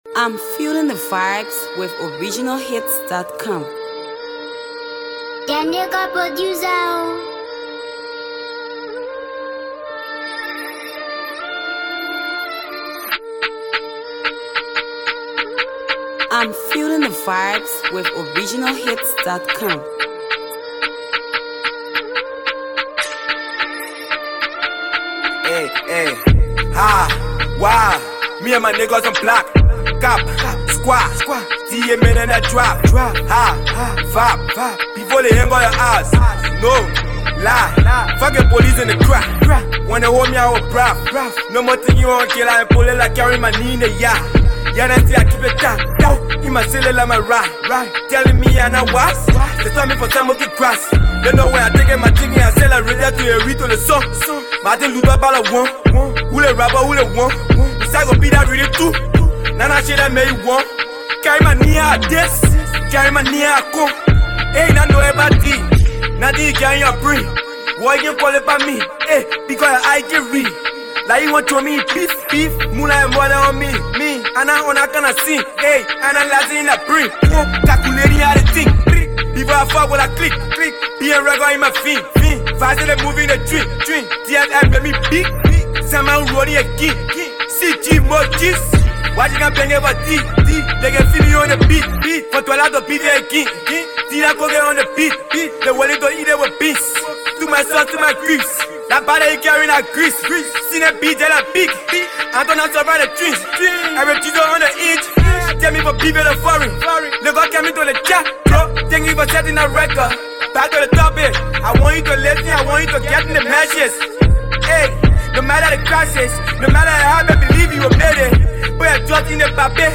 Multi-talented uprising trap artist